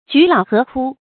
菊老荷枯 jú lǎo hé kū
菊老荷枯发音